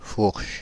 Ääntäminen
Ääntäminen France (Île-de-France): IPA: /fuʁʃ/ Haettu sana löytyi näillä lähdekielillä: ranska Käännöksiä ei löytynyt valitulle kohdekielelle.